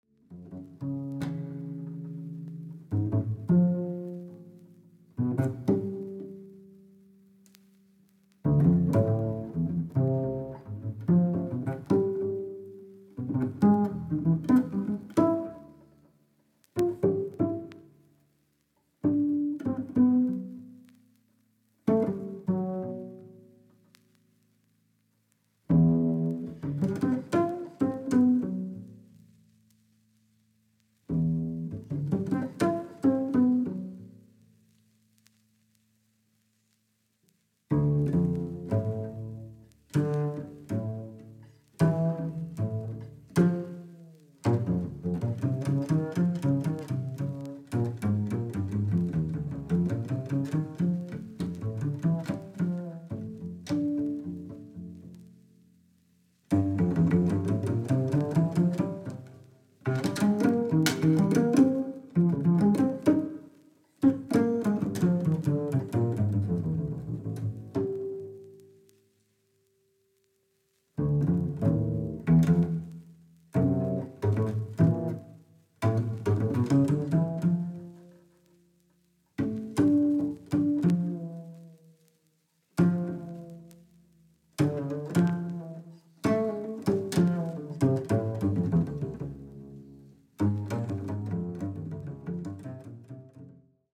Double Bass